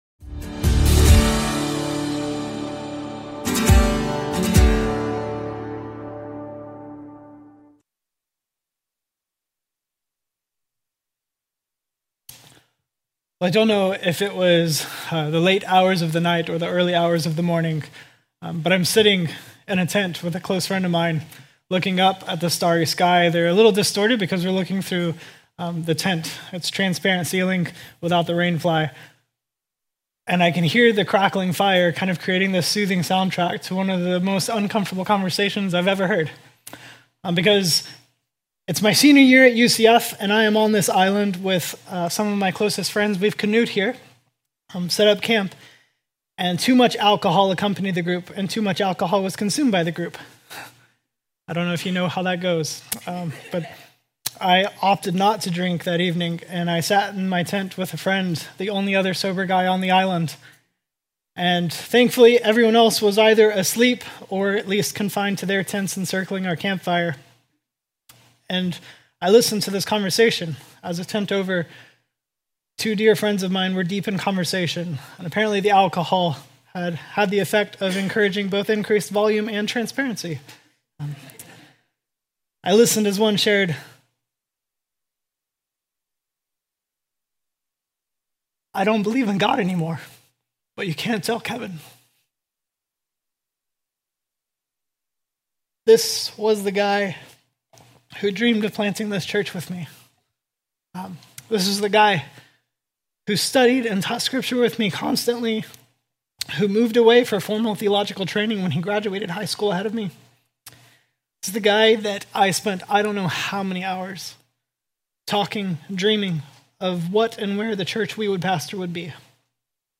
3-2026-Sermon-A.mp3